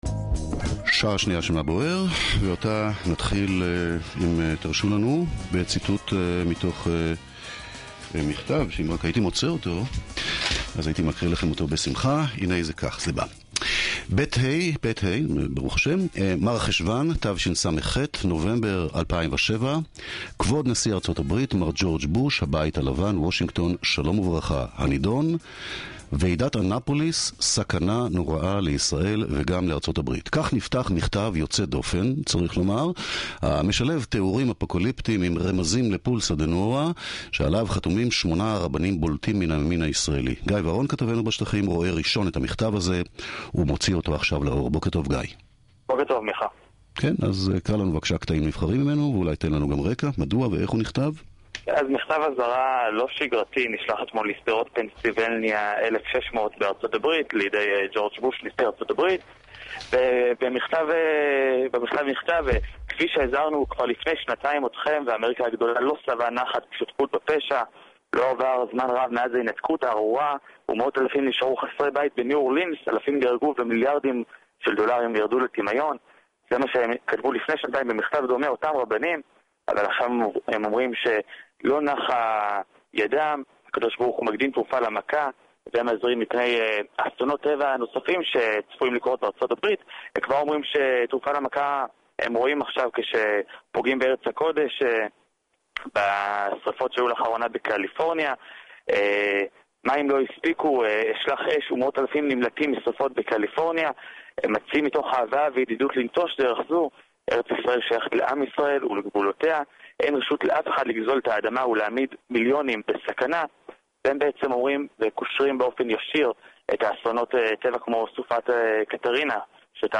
הרב דוד מאיר דרוקמן רב העיר קריית מוצקין התראיין ביום שני לתוכנית "מה בוער" בגלי צה"ל וסיפר על המכתב שנשלח לנשיא ארה"ב מר ג'ורג' בוש שכותרתה "ועידת אנפוליס סכנה לעם ישראל ולממשלת ארה"ב" עליו חתומים שמונה רבנים בישראל. במכתב מוזהרת ממשלת ארה"ב כי אסונות הטבע האחרונים שפגעו בארה"ב הם כתוצאה מנסיונם של האמריקאים להזיז יהודים מארץ ישראל.